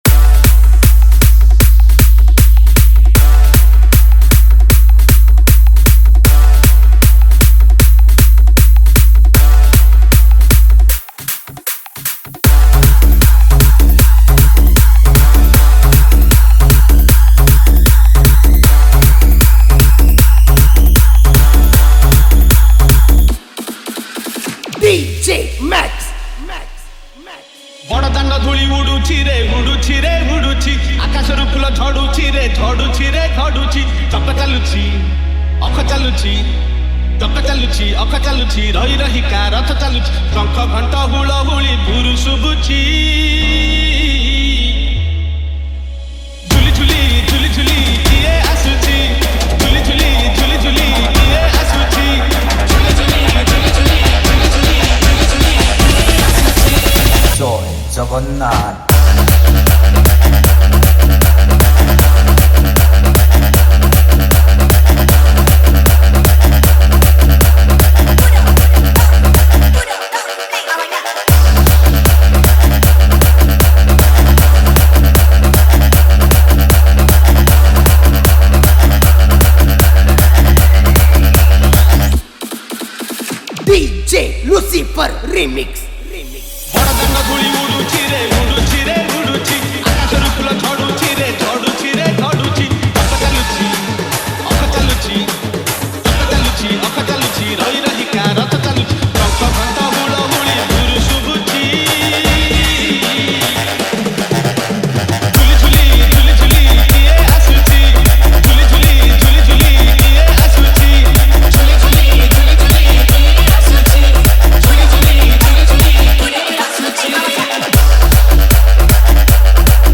Category:  Odia Bhajan Dj 2024